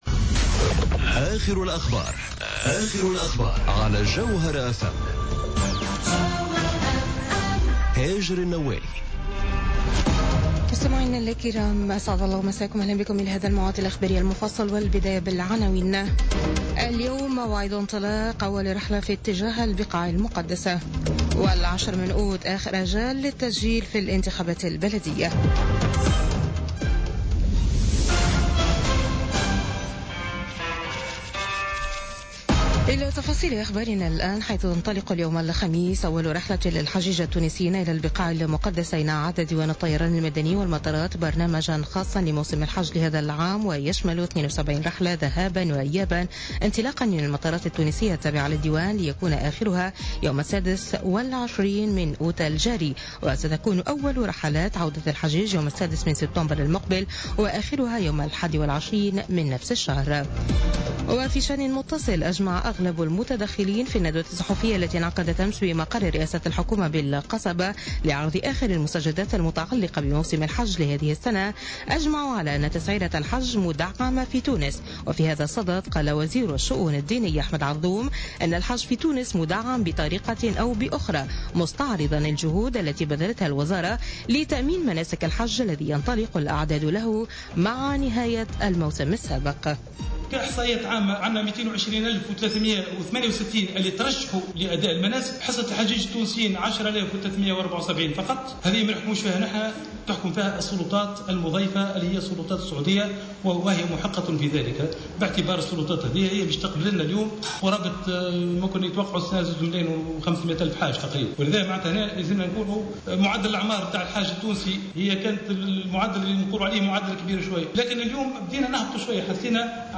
نشرة أخبار منتصف الليل ليوم الخميس 10 أوت 2017